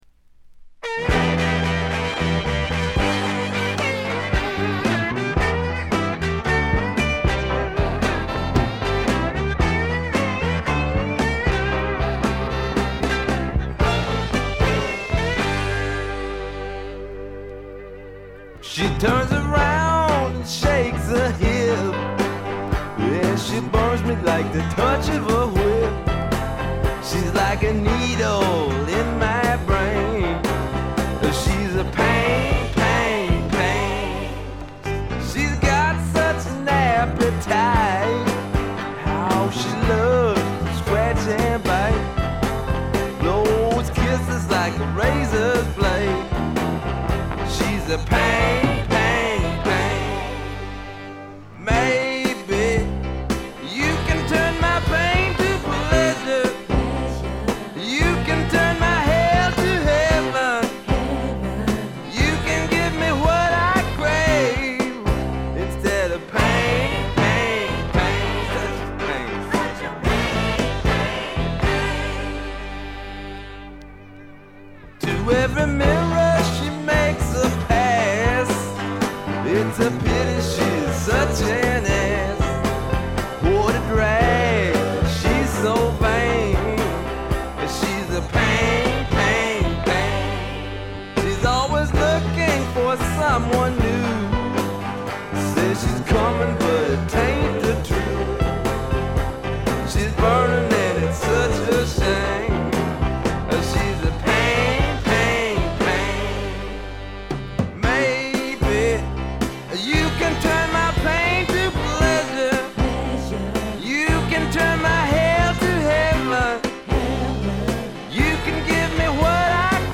ほとんどノイズ感無し。
よりファンキーに、よりダーティーにきめていて文句無し！
試聴曲は現品からの取り込み音源です。